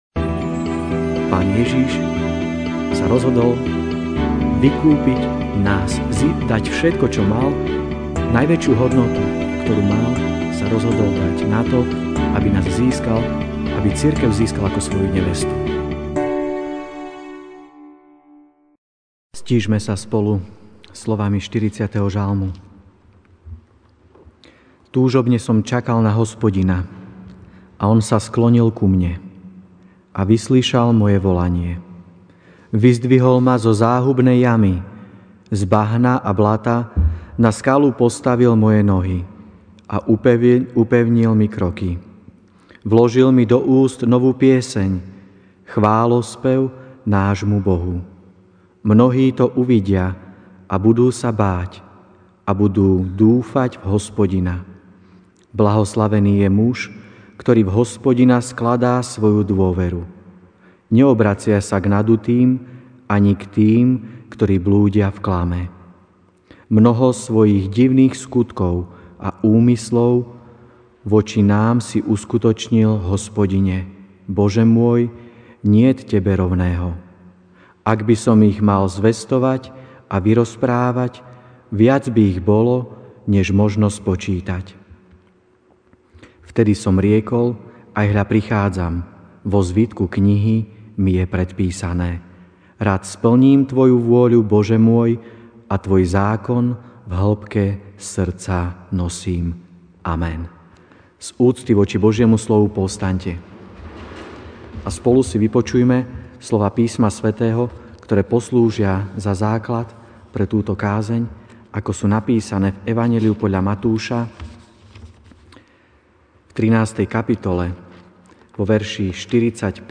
Večerná kázeň: Kráľovstvo nebeské je najvzácnejšie (Mt 13, 45-46)